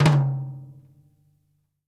FLAM      -L.wav